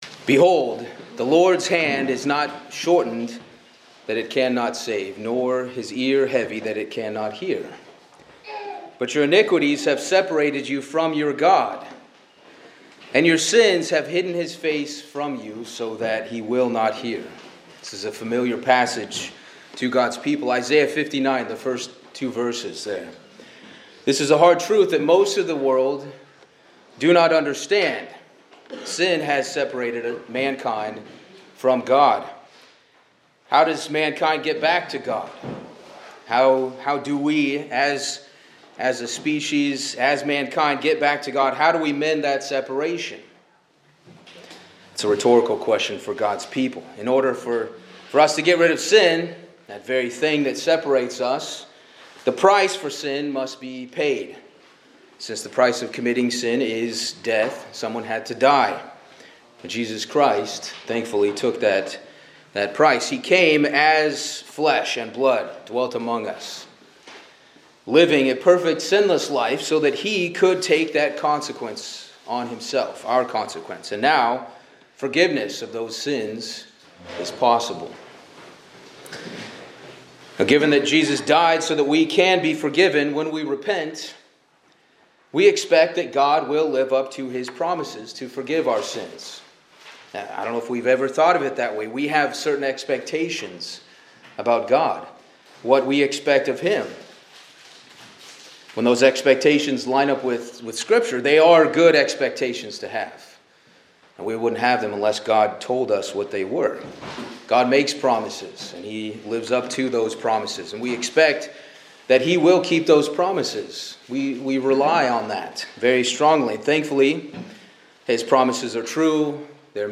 This message teaches that while God’s forgiveness is complete, patient, compassionate, and everlasting, human forgiveness often falls short. Using Psalm 103 and other scriptures, the sermon explains that people tend to forgive selectively, lose patience, desire justice or punishment for others, limit their compassion, and grow weary of forgiving repeatedly.